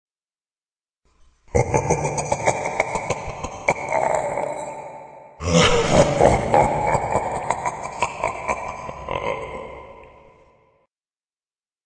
Evil Laughing Sound Effect